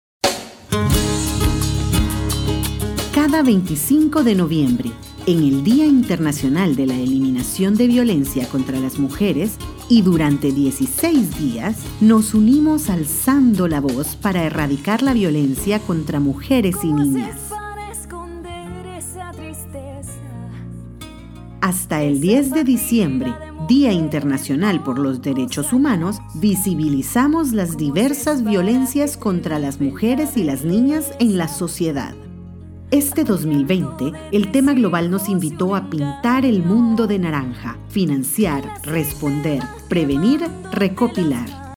Native Spanish speaker, english voiceover, cheerful, bright, serious, convincing, conversational, corporate, educational, commercial spots.
Sprechprobe: Sonstiges (Muttersprache):